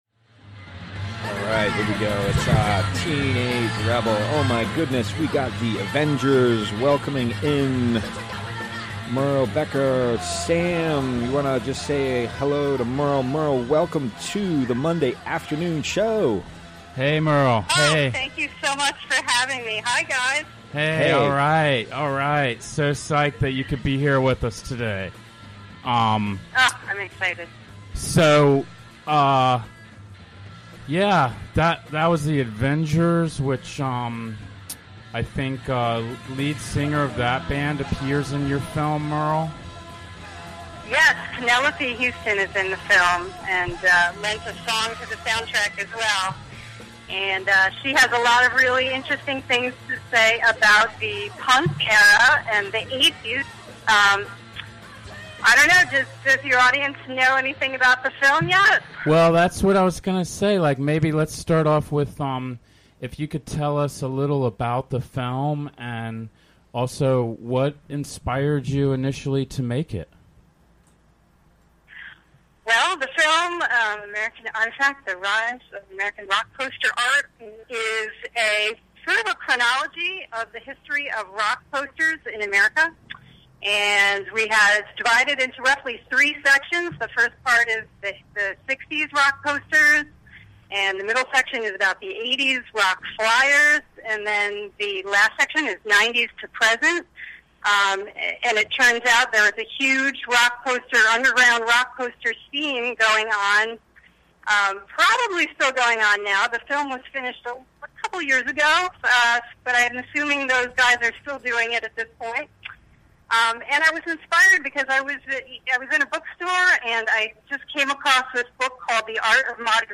Recorded during the WGXC Afternoon Show Monday, July 17, 2017.